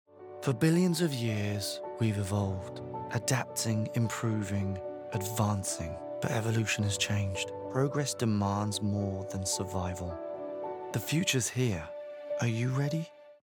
English (Neutral - Mid Trans Atlantic)
Adult (30-50) | Yng Adult (18-29)